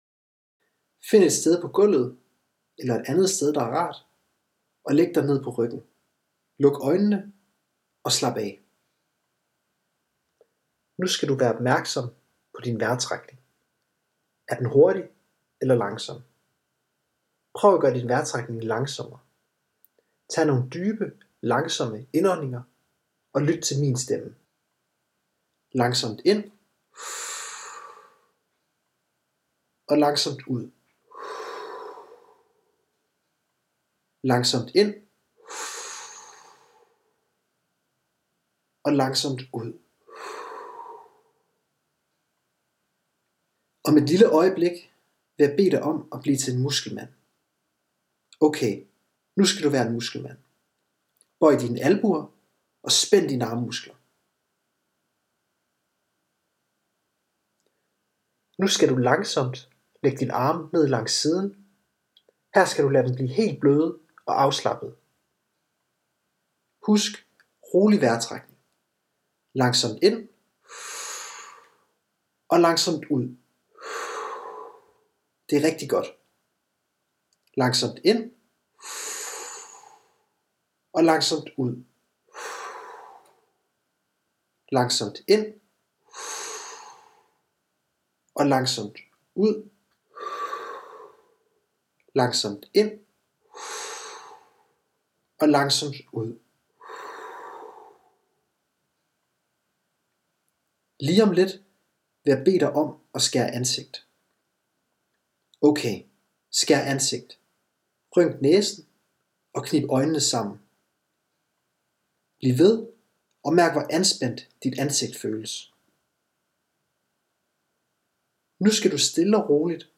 Afspændingsøvelse.mp3